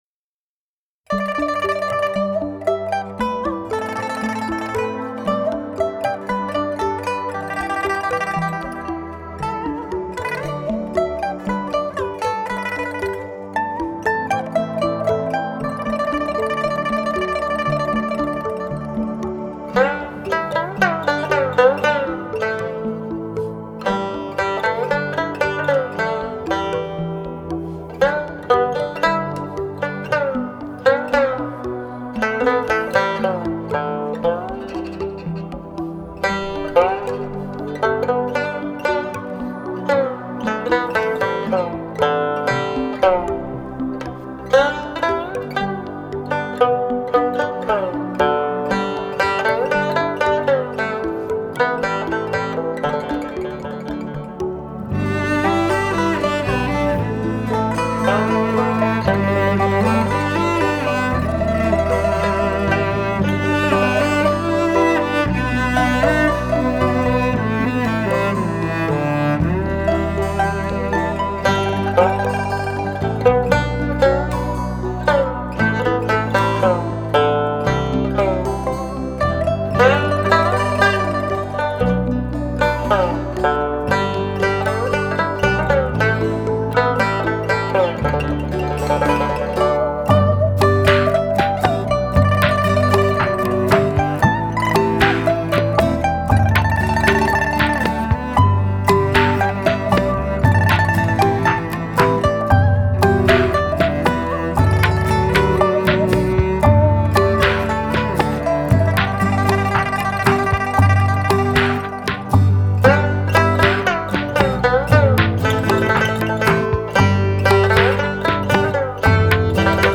0007-三弦名曲浏阳河.mp3